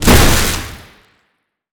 electric_lightning_blast_01.wav